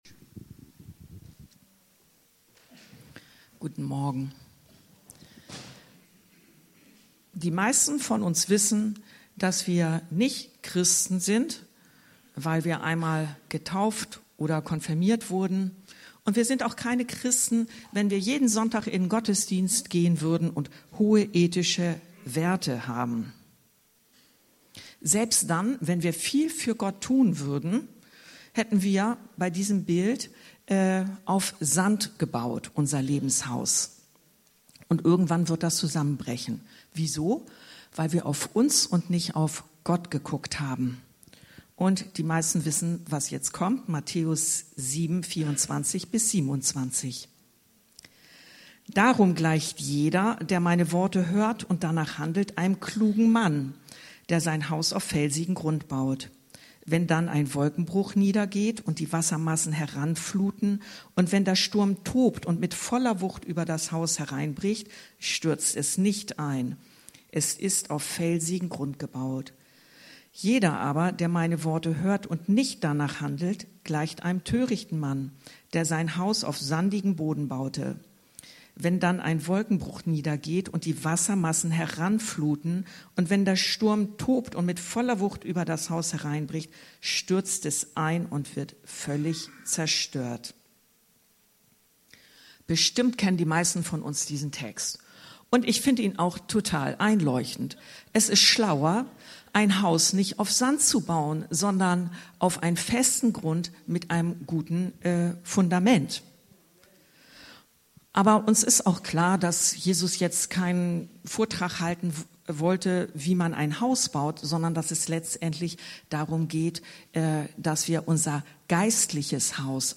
Darf Gott Dein Gott sein? ~ Anskar-Kirche Hamburg- Predigten Podcast